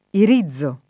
irizzo [ ir &zz o ]